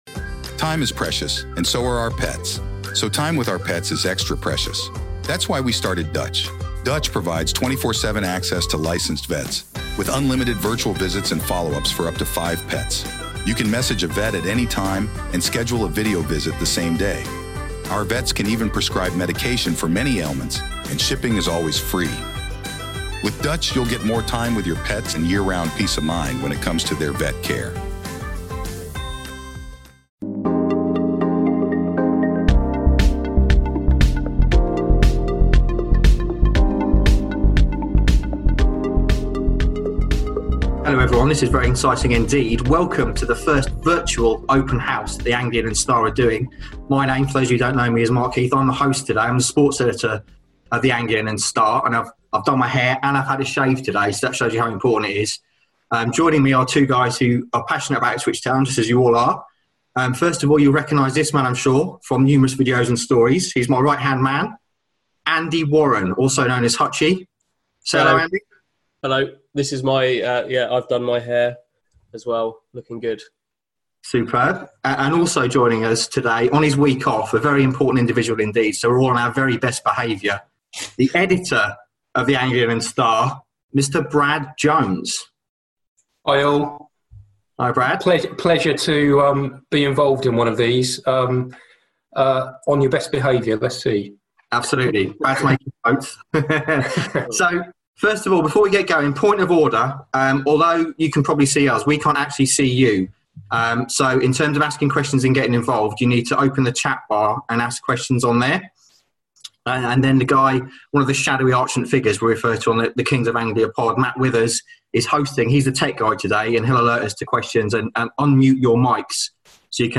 We held our first virtual Open House chat this week, looking at Ipswich Town - and it proved to be an interesting and lively debate.